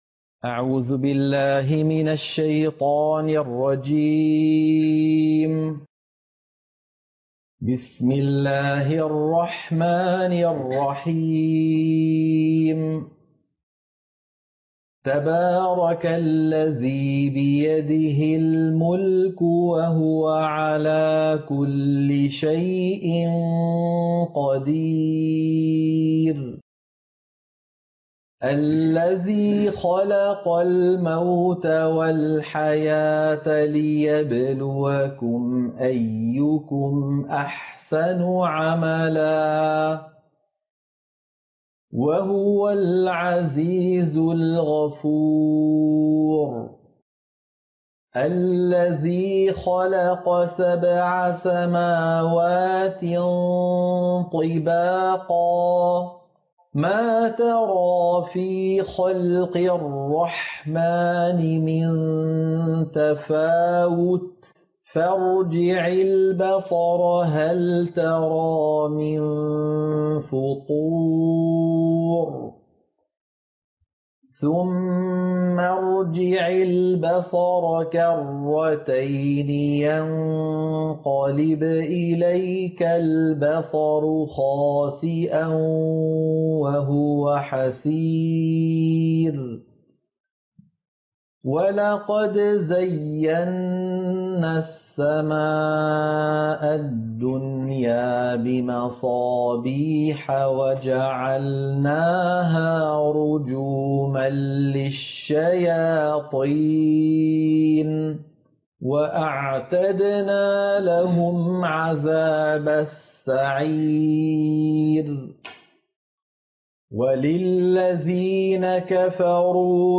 سورة الملك - القراءة المنهجية - الشيخ أيمن سويد